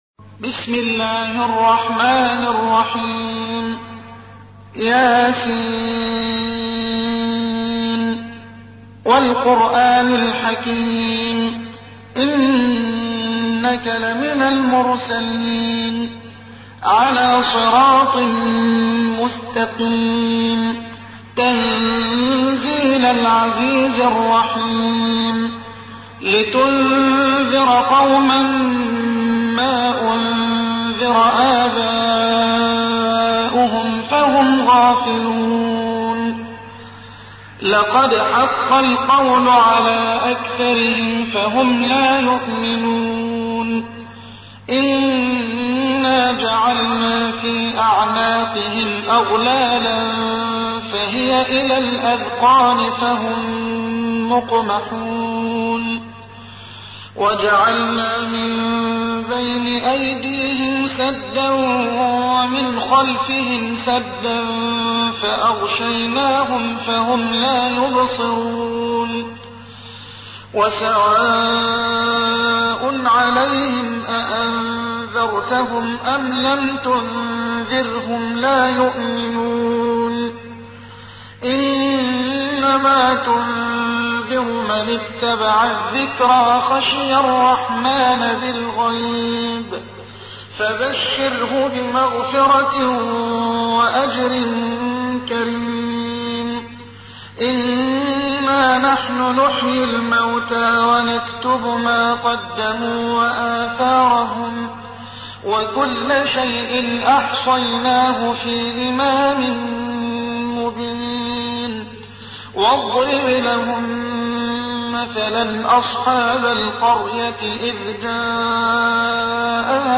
تحميل : 36. سورة يس / القارئ محمد حسان / القرآن الكريم / موقع يا حسين